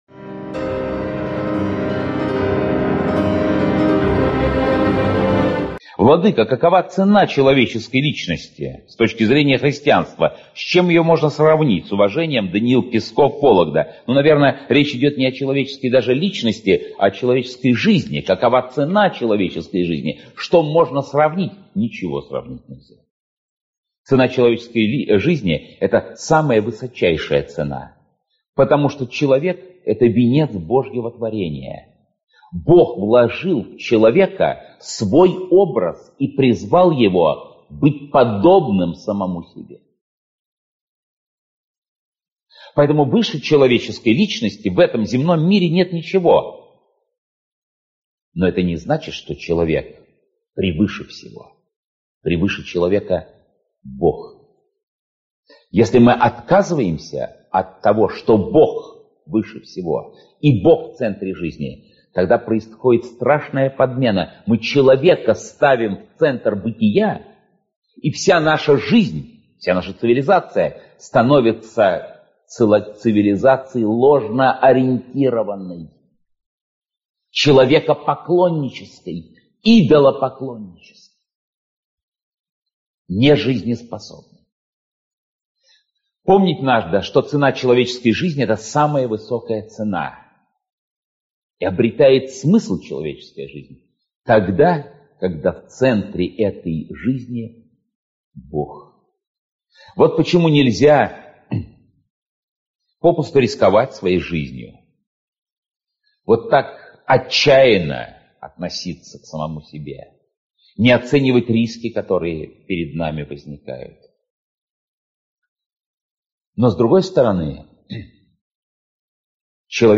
Аудиокнига Первосвятительское слово. Беседы Патриарха Кирилла | Библиотека аудиокниг
Беседы Патриарха Кирилла Автор Святейший Патриарх Московский и всея Руси Кирилл Читает аудиокнигу Святейший Патриарх Московский и всея Руси Кирилл.